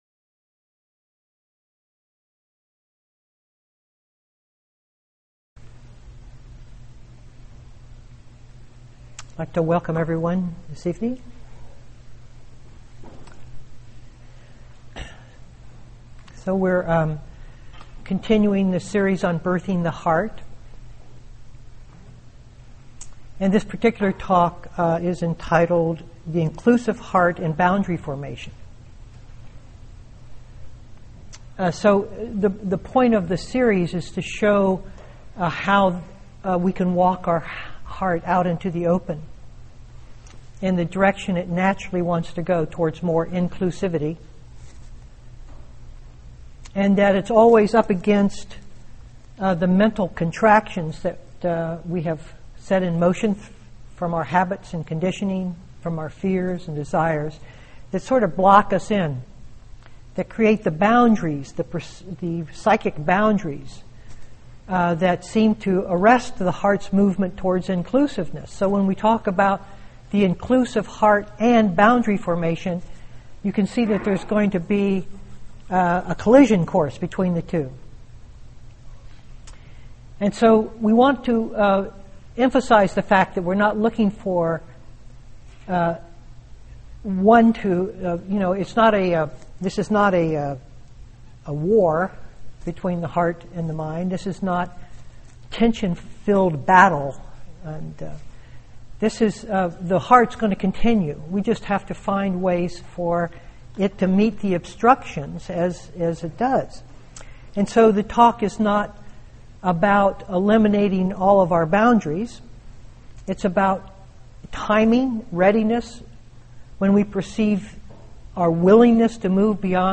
2014-04-29 Venue: Seattle Insight Meditation Center